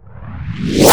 VEC3 Reverse FX
VEC3 FX Reverse 01.wav